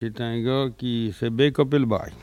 Saint-Jean-de-Monts
Langue Maraîchin
Catégorie Locution